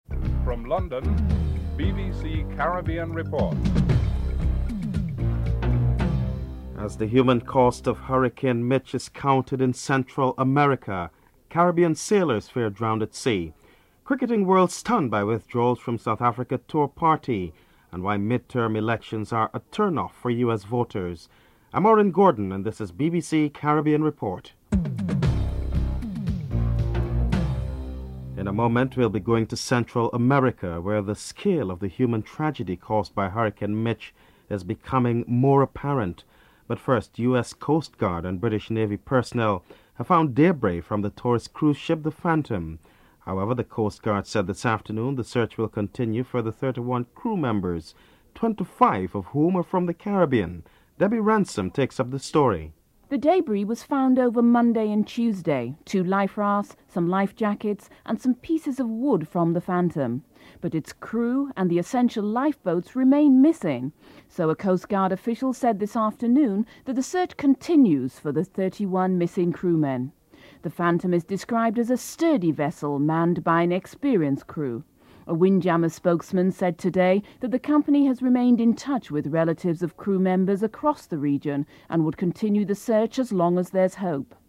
1. Headlines (00:00-00:27)
Ambassador Brindley Benn is interviewed (09:32-12:48)